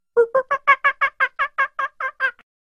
Pets And Animals Ringtones